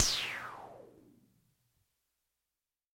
crash
描述：part of drums
标签： cymbals crash experimental electronic electro
声道单声道